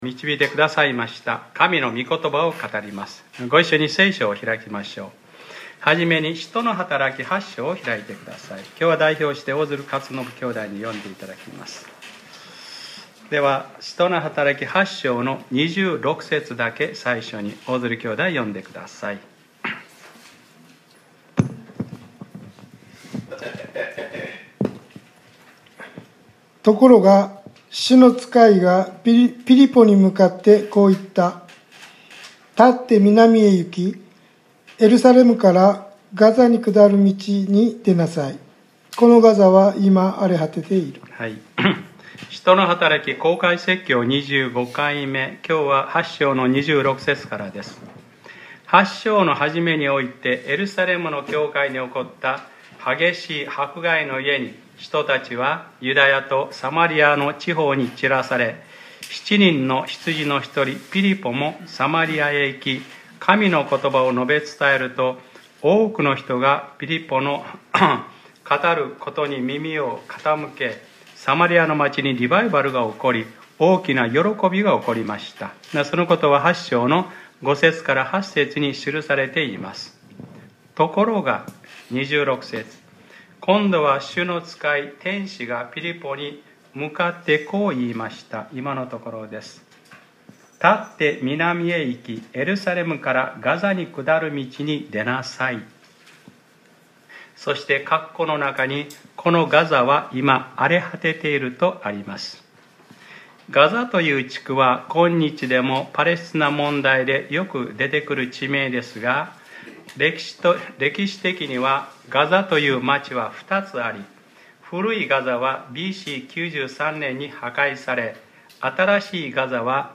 2018年07月01日（日）礼拝説教『使徒ｰ25：クリスチャンコーチング』